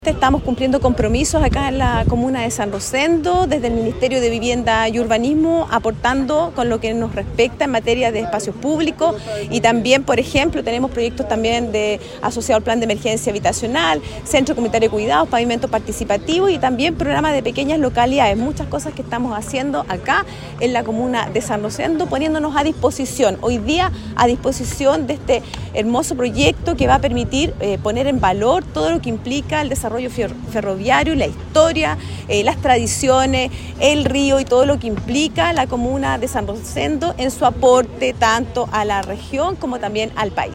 Por su parte, la seremi de de Vivienda y Urbanismo, Claudia Toledo, se refirió a las acciones y compromisos gubernamentales que se están desarrollando en San Rosendo.